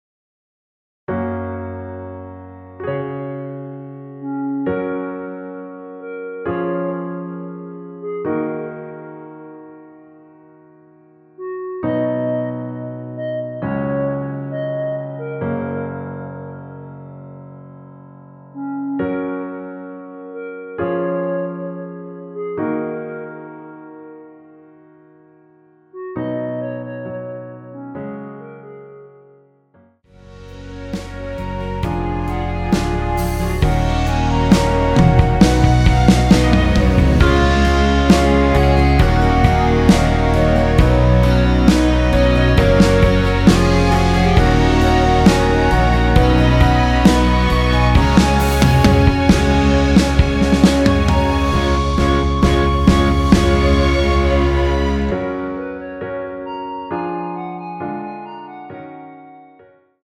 전주없이 시작하는 곡이라 1마디 전주 만들어 놓았습니다.(미리듣기 참조)
원키에서(+5)올린 멜로디 포함된 MR입니다.(미리듣기 확인)
F#
앞부분30초, 뒷부분30초씩 편집해서 올려 드리고 있습니다.
중간에 음이 끈어지고 다시 나오는 이유는